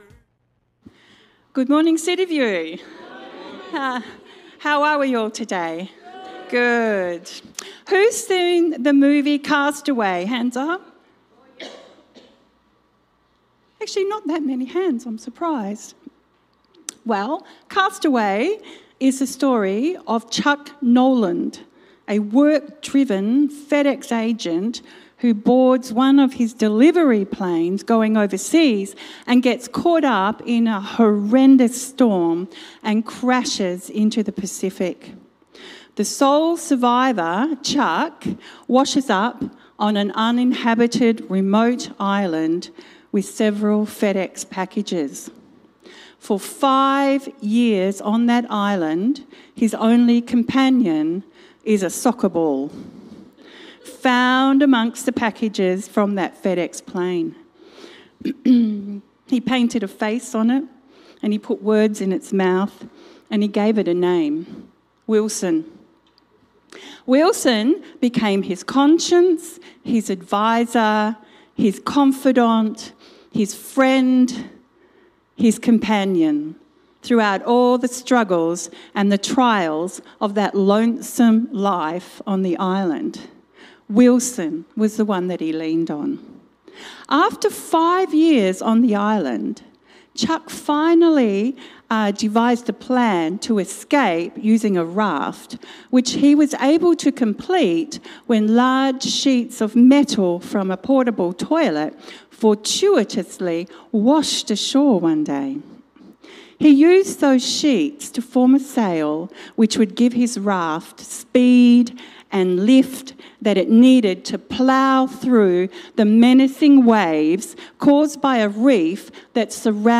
Sermon
It features Wilson- a volleyball and the main character’s only friend in the movie Cast Away. Media The following video clip played near the beginning of the sermon.